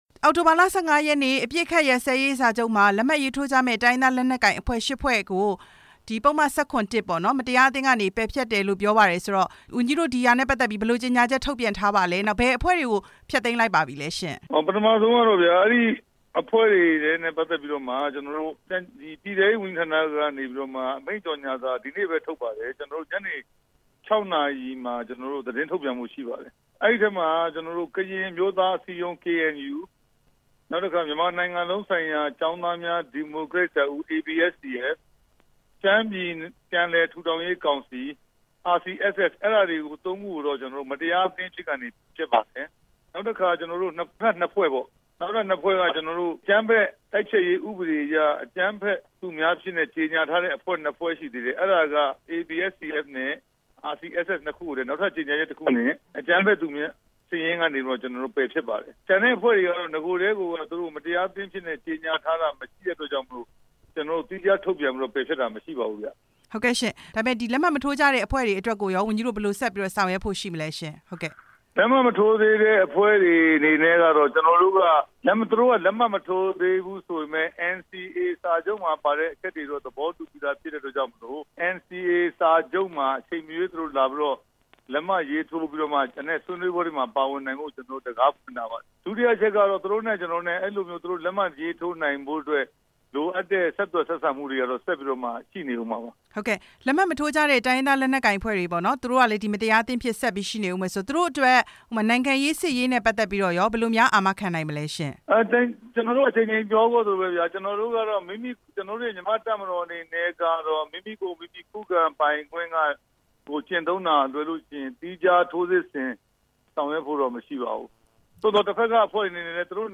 ဦးရဲထွဋ် ကို မေးမြန်းချက်